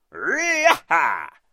звук лихого ковбоя перед ударом кнута по скоту